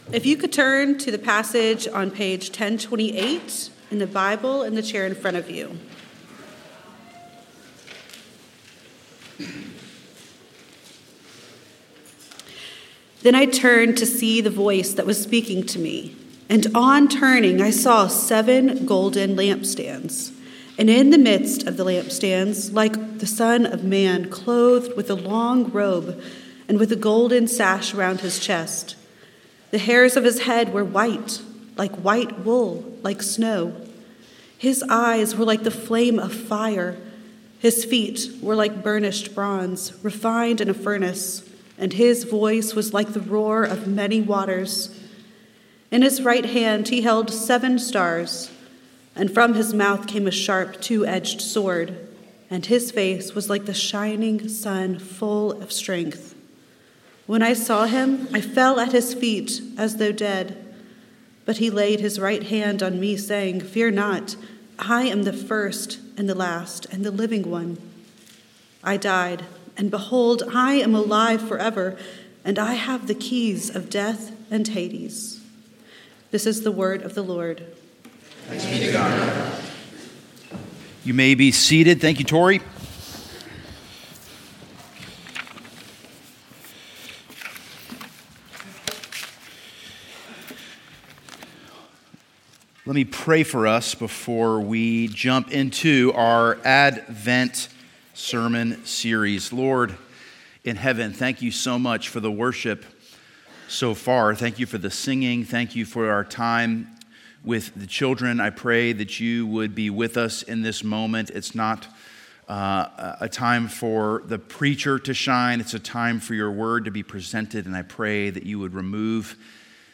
Sermons - Grace Presbyterian Church
Sermons and other teaching from Grace Presbyterian Church in Columbia, SC.